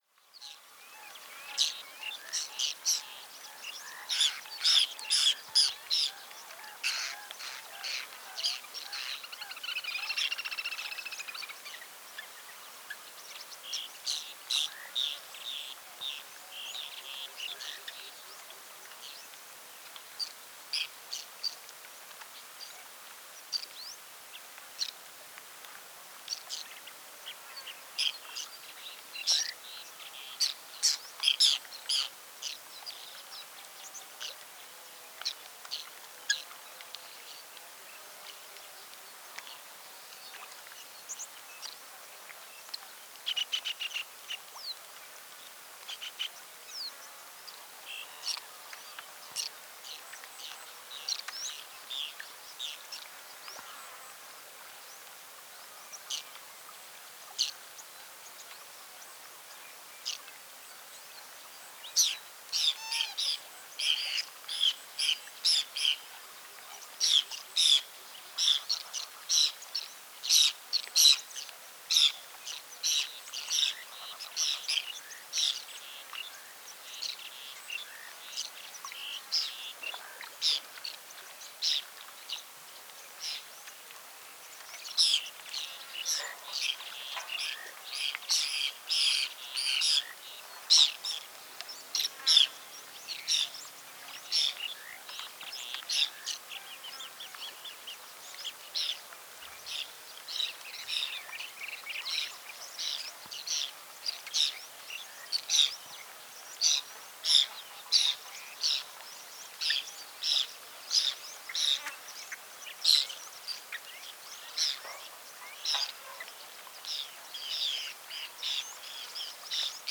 Gaivina de cara branca
Canto